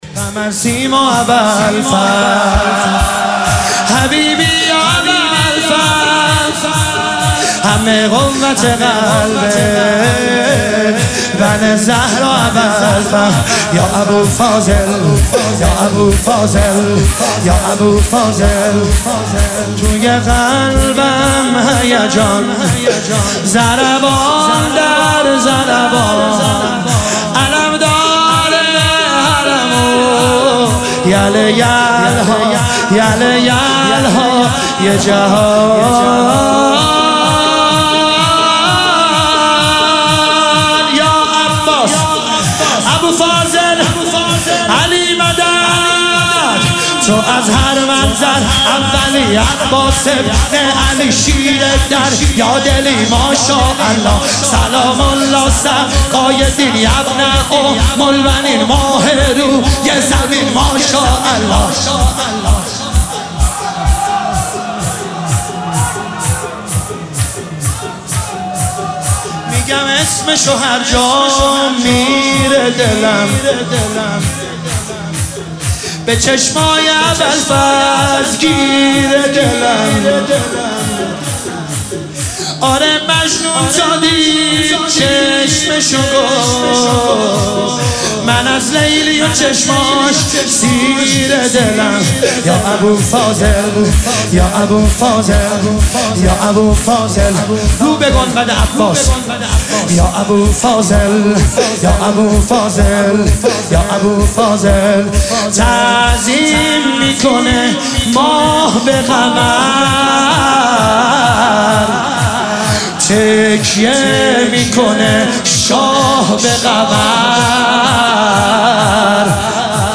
مداحی شور